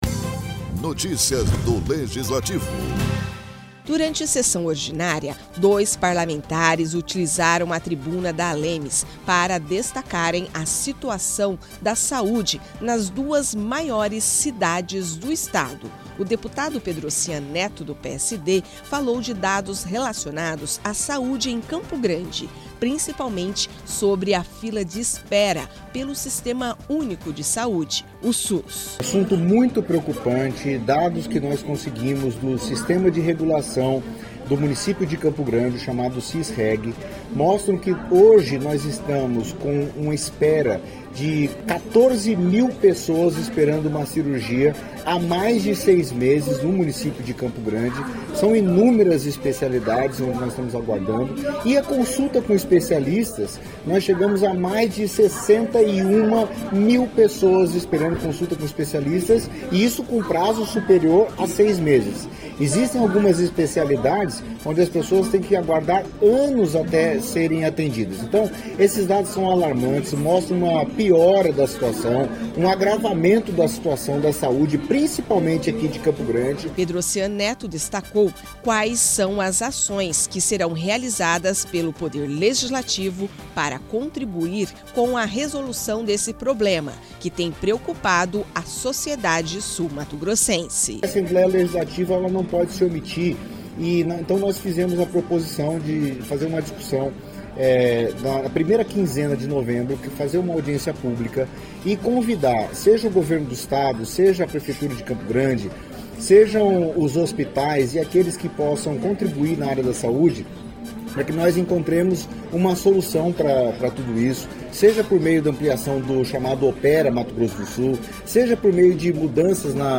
Durante sessão ordinária, dois parlamentares utilizaram a tribuna da Assembleia Legislativa de Mato Grosso do Sul (ALEMS), para destacarem a situação saúde das duas maiores cidades do Estado.